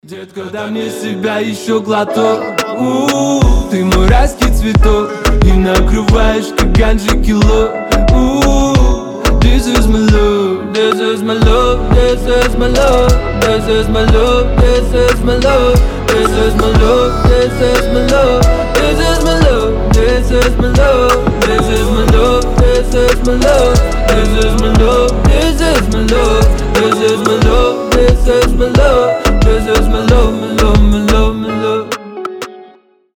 • Качество: 320, Stereo
дуэт
Moombahton